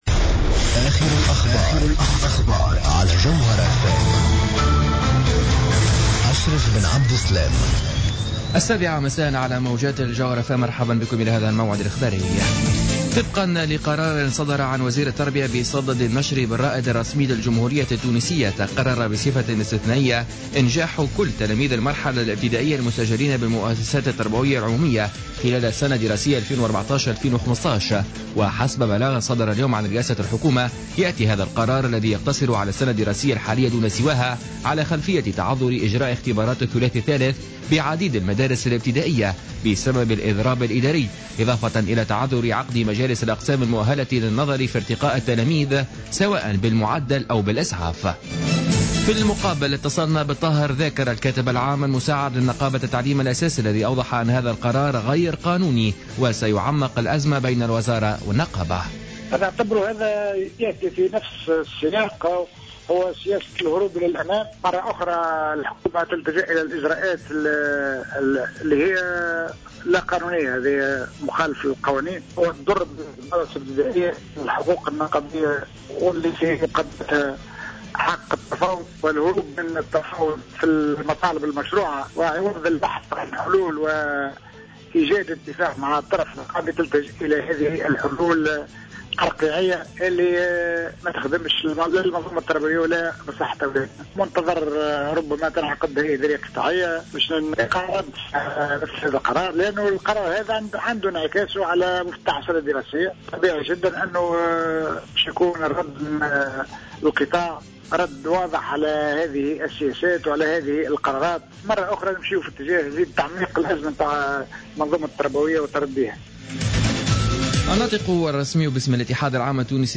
نشرة أخبار السابعة مساء ليوم الخميس 11 جوان 2015